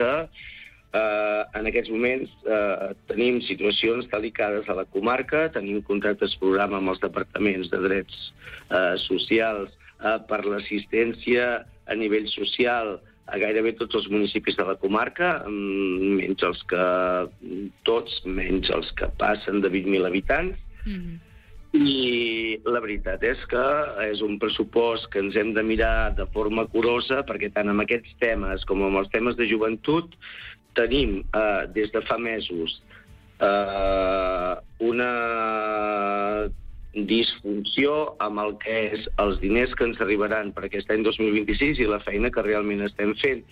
En una entrevista concedida al programa Supermatí, el president del Consell Comarcal del Baix Empordà, Enric Marquès, ha explicat que el pressupost del 2026, que puja fins als 37 milions d’euros, representa un increment del 5,5% respecte a l’any anterior, i està pensat per millorar serveis i adequar-se als increments salarials de la plantilla.